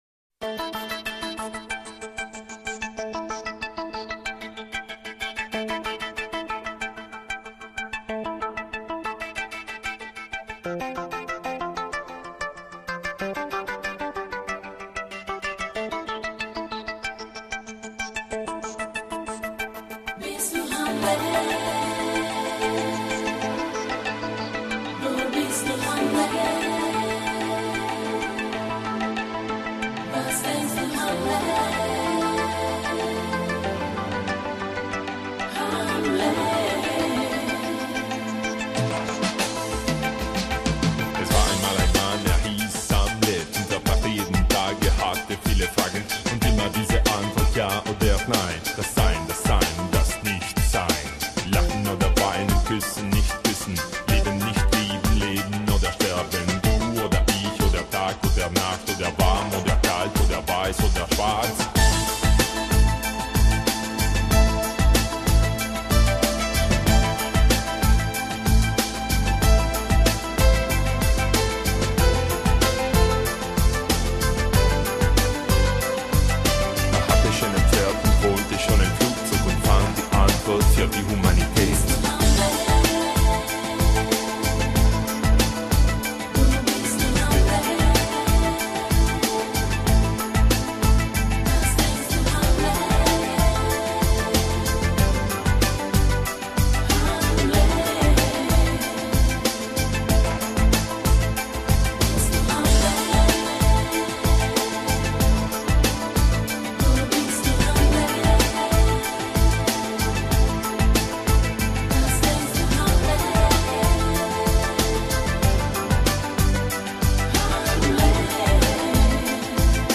Назад в Exclusive EuroDance 90-х